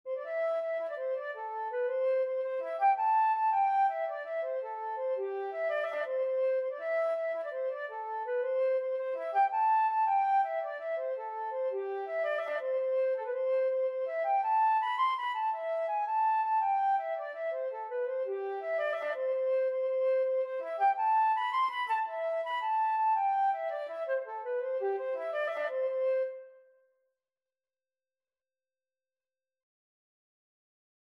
Flute version
Traditional Music of unknown author.
9/8 (View more 9/8 Music)
C major (Sounding Pitch) (View more C major Music for Flute )
Traditional (View more Traditional Flute Music)